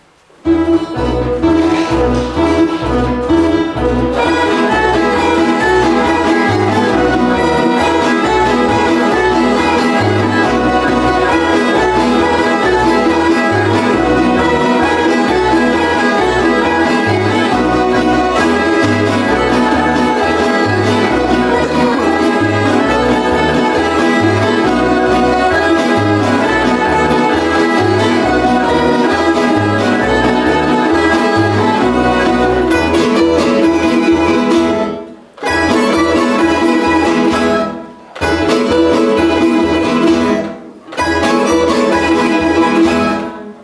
Mladi tamburaši ansambla unijeli su dodatni šarm u program završnog predstavljanja projekta